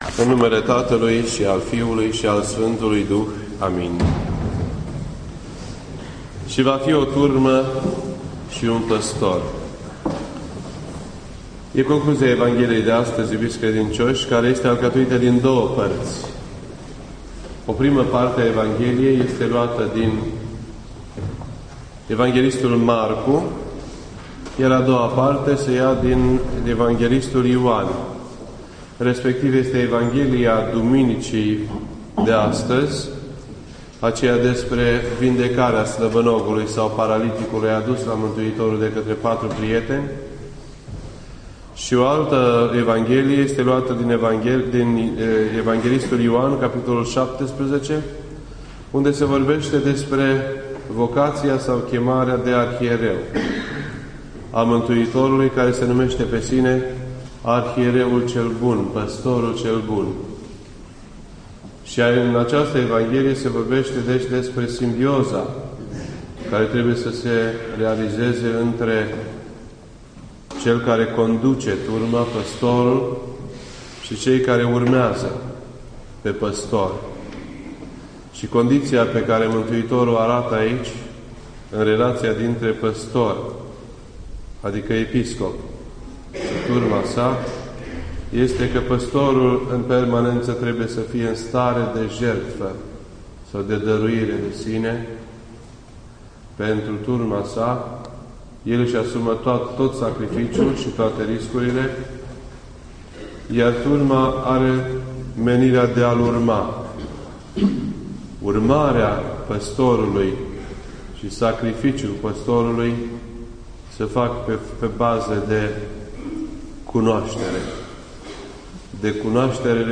This entry was posted on Sunday, March 11th, 2012 at 8:41 PM and is filed under Predici ortodoxe in format audio.